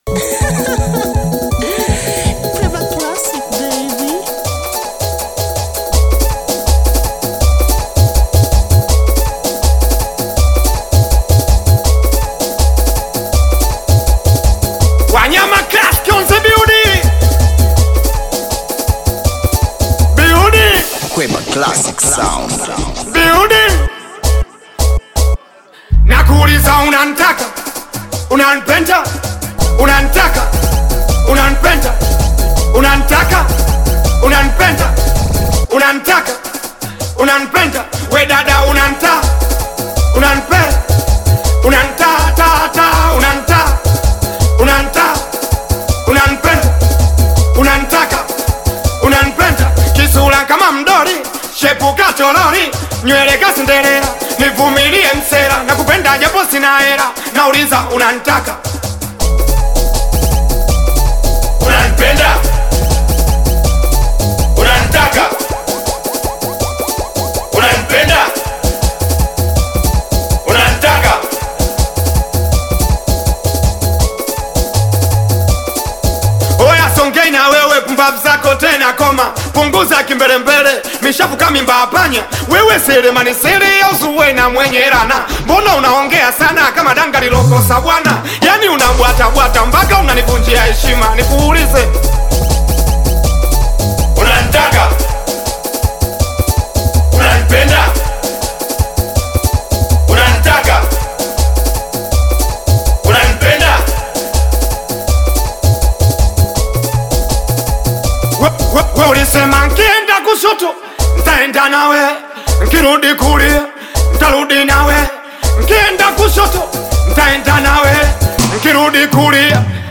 Singeli music track
Singeli song